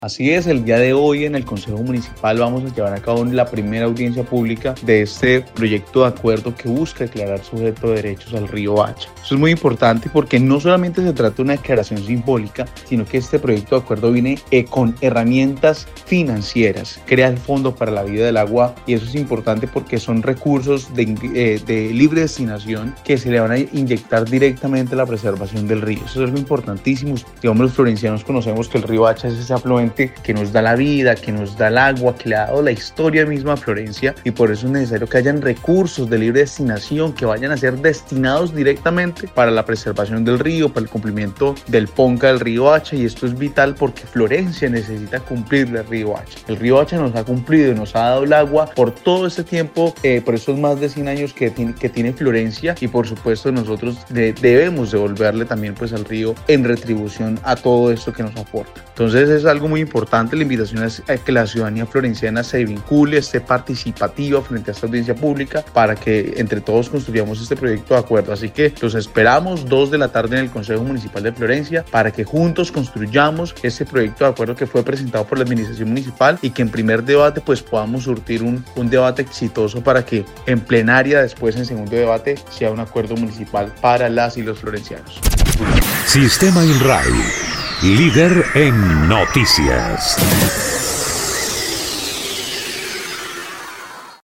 Erick Mauricio Sánchez, concejal por Alianza Verde del municipio, explicó que, la idea es dar a conocer todos los detales administrativos, jurídicos y financieros del proyecto presentado por la administración Monsalve Ascanio, que logren preservar a la principal fuente hídrica de la ciudad.
03_CONCEJAL_ERICK_SANCHEZ_AUDIENCIA.mp3